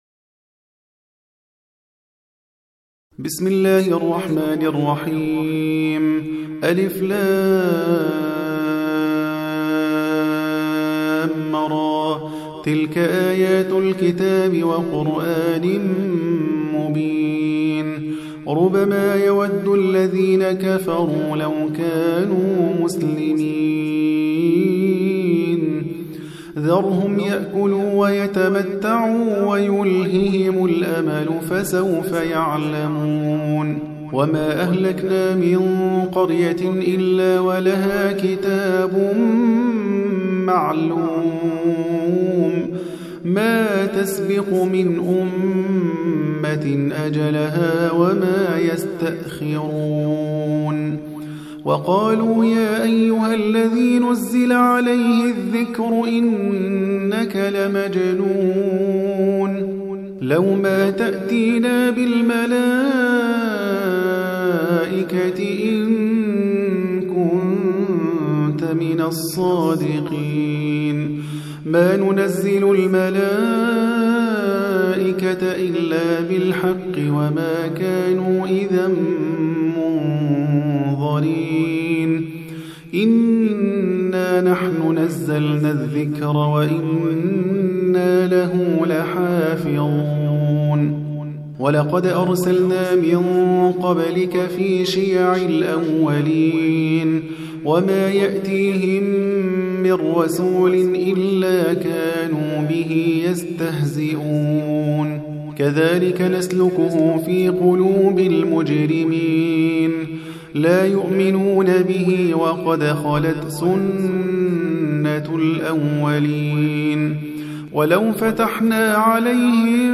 15. Surah Al-Hijr سورة الحجر Audio Quran Tarteel Recitation
Surah Sequence تتابع السورة Download Surah حمّل السورة Reciting Murattalah Audio for 15. Surah Al-Hijr سورة الحجر N.B *Surah Includes Al-Basmalah Reciters Sequents تتابع التلاوات Reciters Repeats تكرار التلاوات